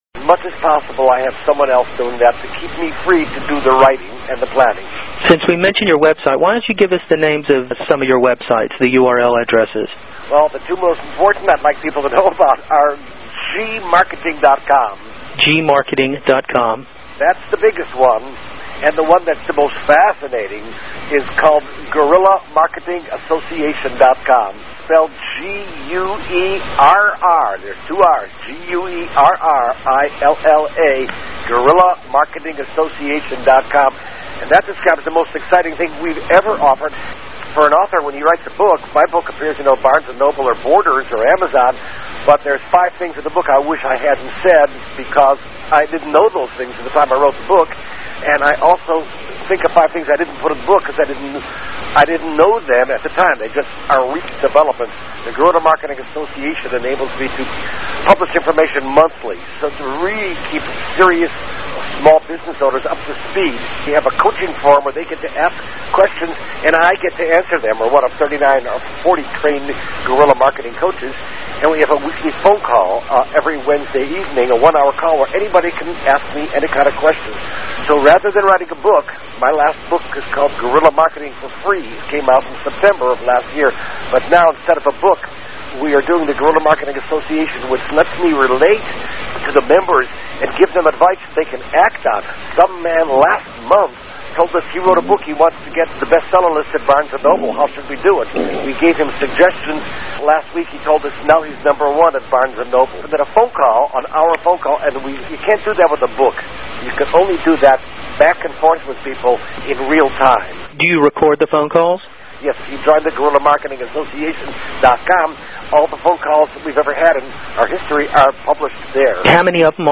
Exclusive 120-Minute Interview with Jay Conrad Levinson
JayConradLevensonInterview2.mp3